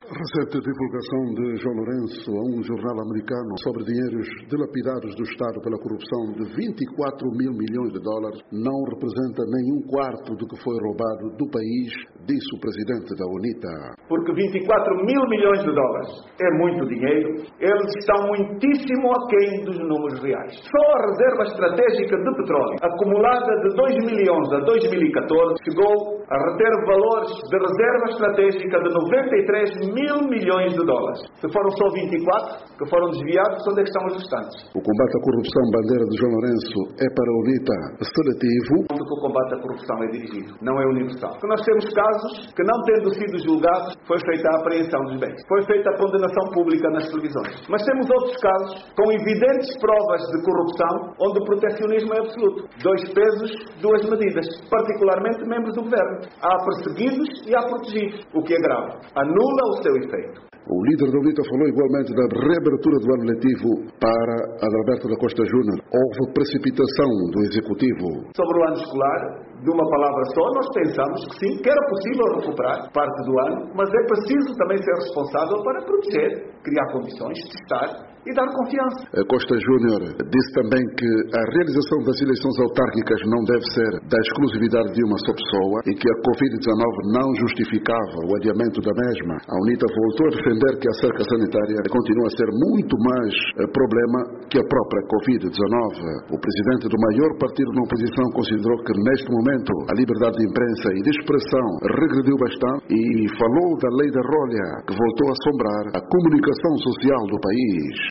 Adalberto Costa Júnior em conferência de imprensa - 1:48